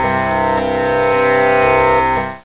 1 channel
sitar.mp3